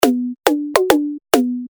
без слов
барабаны
Как будто удары по игрушечному барабанчику